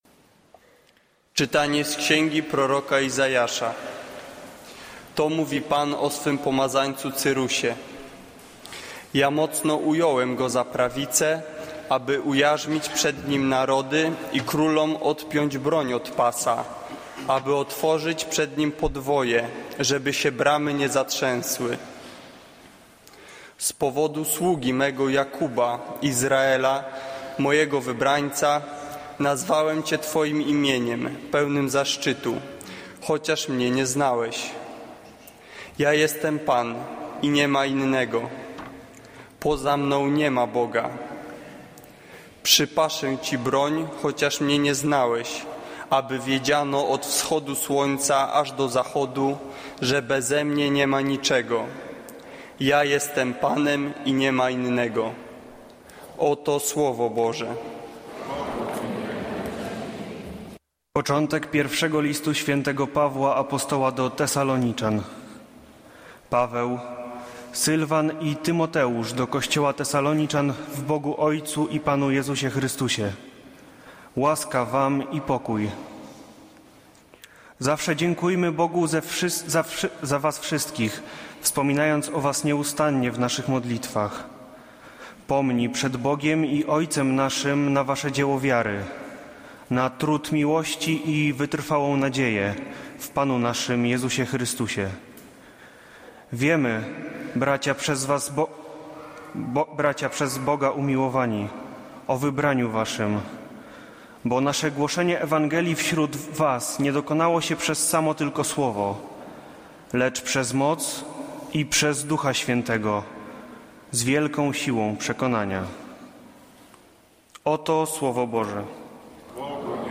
Kazanie z 19 października 2014
niedziela, godzina 15:00, kościół św. Anny w Warszawie « Kazanie z 15 czerwca 2014r.